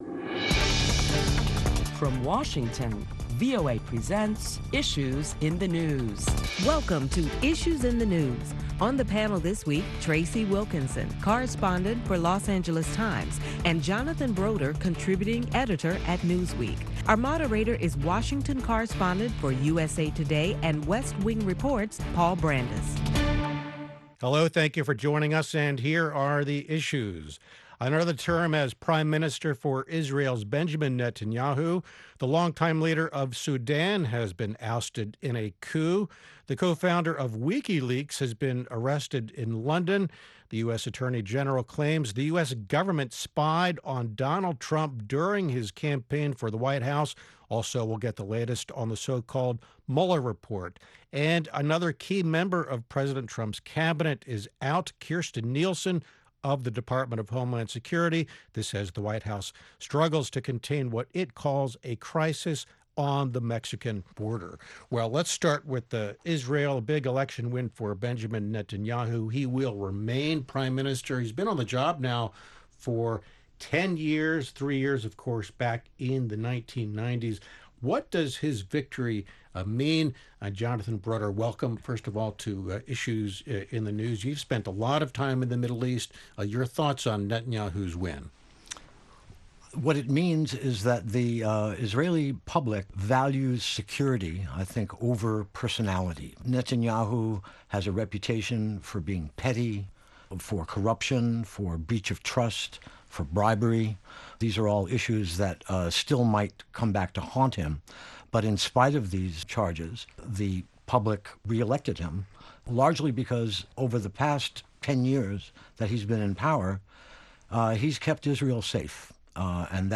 Listen to a panel of prominent Washington journalists as they deliberate the week's headline news beginning with the arrest of WikiLeaks founder, Julian Assange.